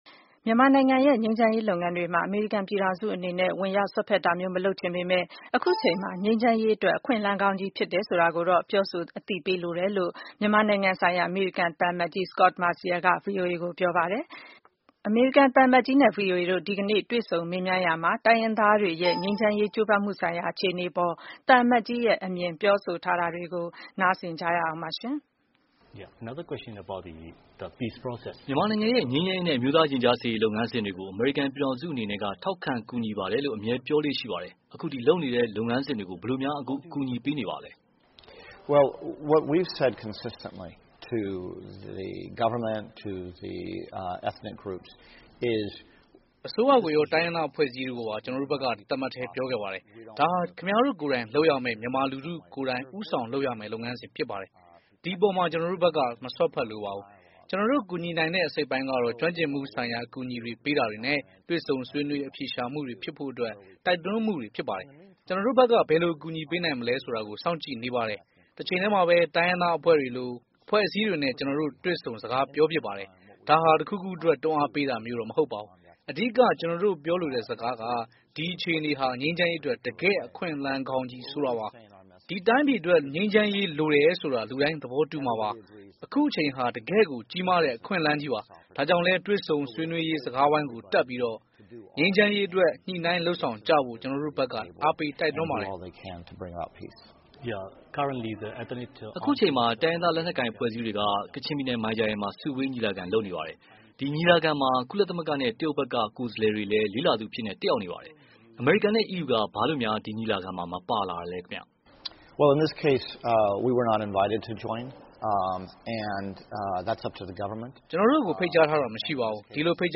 မြန်မာ့ငြိမ်းချမ်းရေးလုပ်ငန်းစဉ် အမေရိကန်ပံ့ပိုးမည် ( ဗွီအိုအေနှင့် အမေရိကန်သံအမတ် သီးသန့်တွေ့ဆုံခန်း)